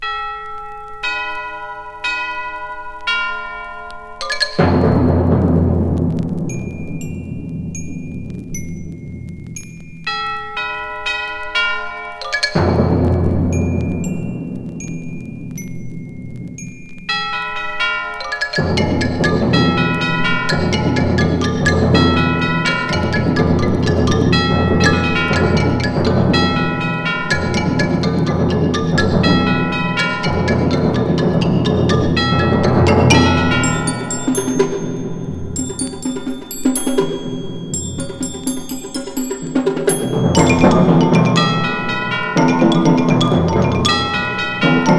Classical, Contemporary　France　12inchレコード　33rpm　Stereo